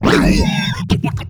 hurt1.wav